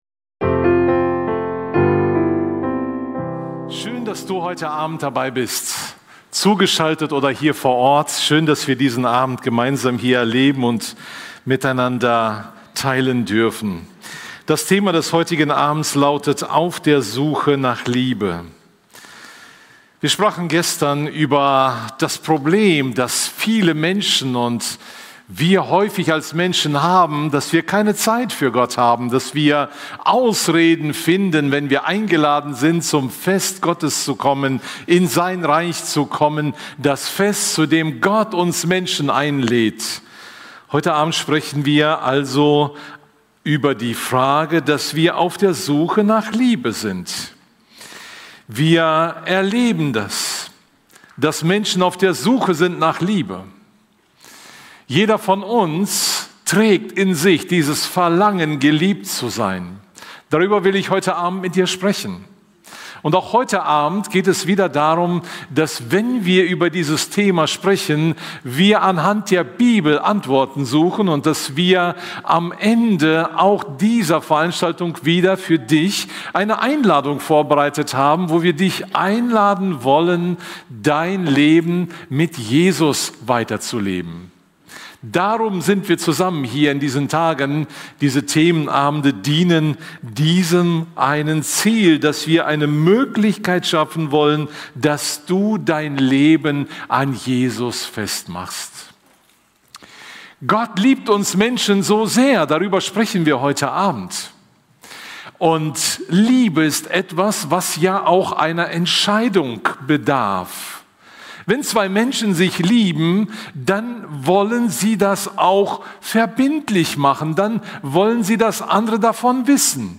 Höre inspirierende Predigten und lerne Jesus besser kennen.